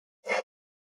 557まな板の上,包丁,ナイフ,調理音,料理,
効果音